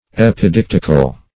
Search Result for " epidictical" : The Collaborative International Dictionary of English v.0.48: Epidictic \Ep`i*dic"tic\, Epidictical \Ep`i*dic"tic*al\, a. [L. epidictius.